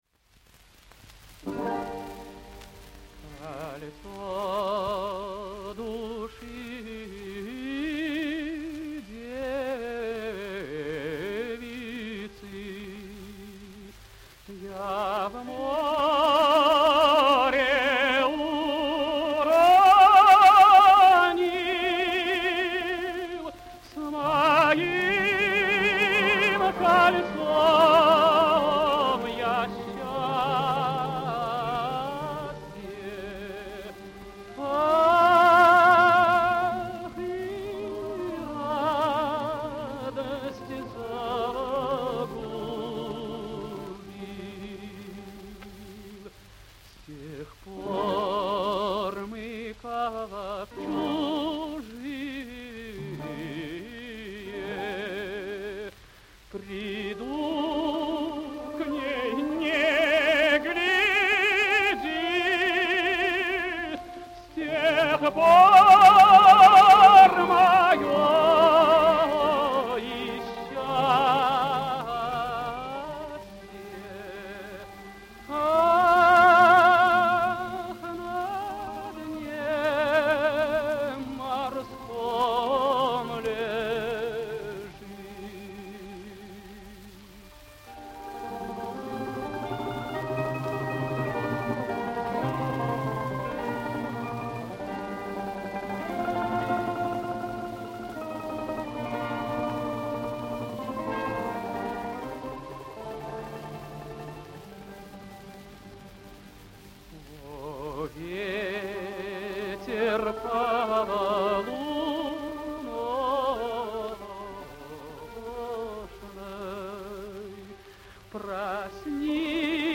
Русская народная песня